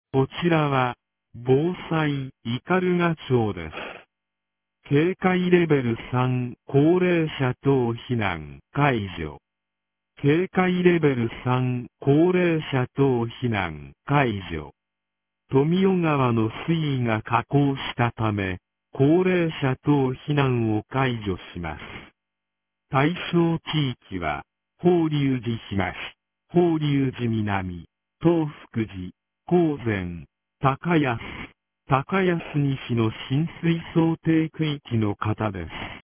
2023年06月02日 14時41分、斑鳩町より放送がありました。